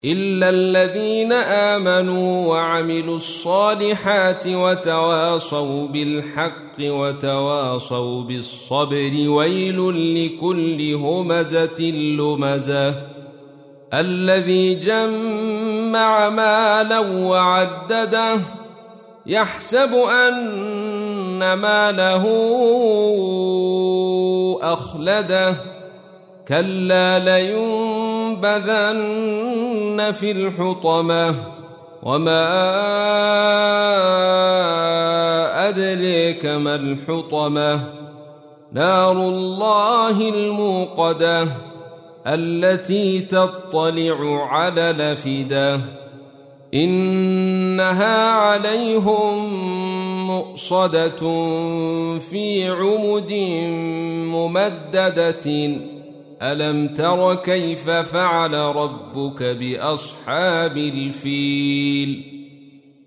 تحميل : 104. سورة الهمزة / القارئ عبد الرشيد صوفي / القرآن الكريم / موقع يا حسين